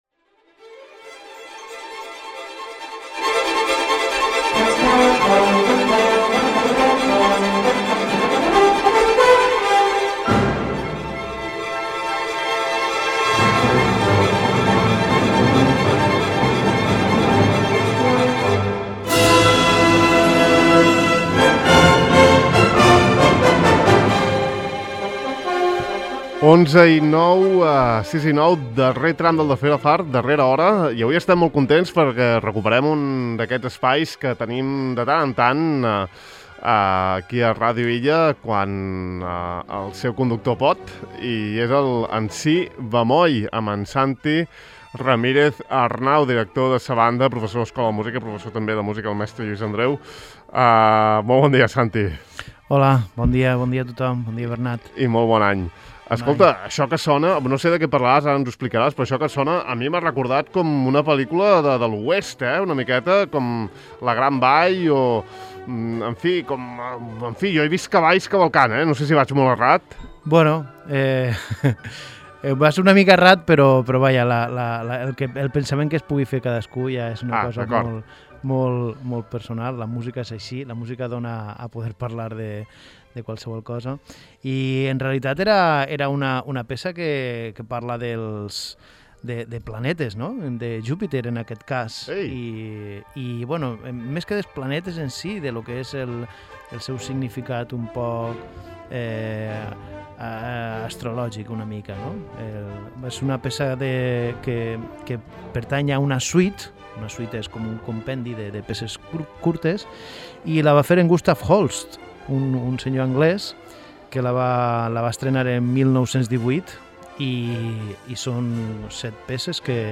Holst, Donizetti, Mussorgsky, Gershwin, Prokofiev, Stravinsky o Ravel entre alguns dels autors que hem escoltat aquest matí en el seu espai.